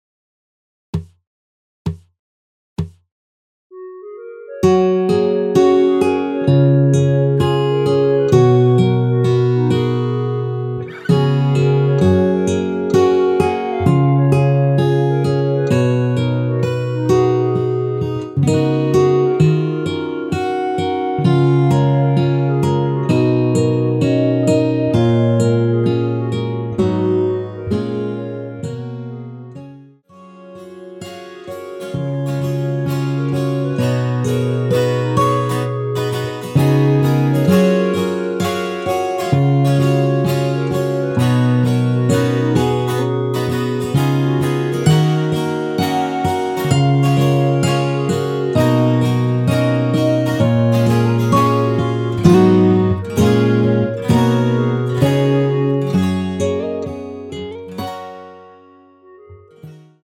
전주없이 시작 하는 곡이라 카운트 넣어 놓았습니다.(미리듣기 참조)
원키에서(+3)올린 멜로디 포함된 MR입니다.
F#
앞부분30초, 뒷부분30초씩 편집해서 올려 드리고 있습니다.